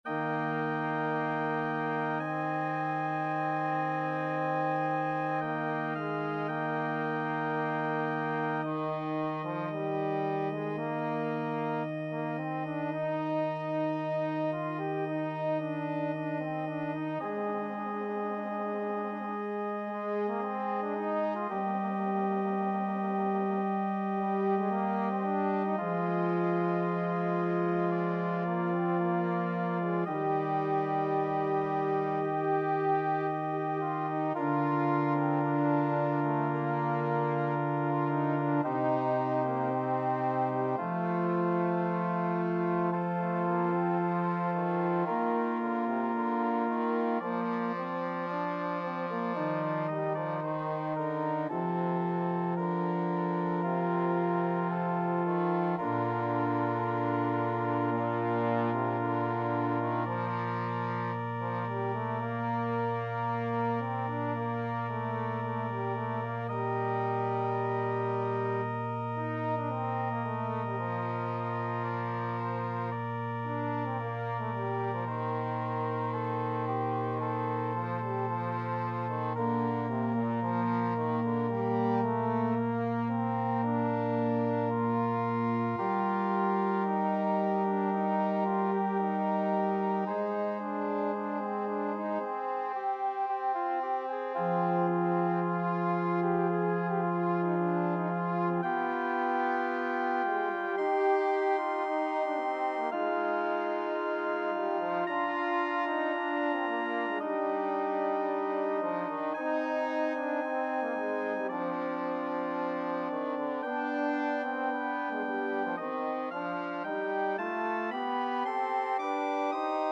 4/4 (View more 4/4 Music)
=56 Adagio
Classical (View more Classical Trombone Music)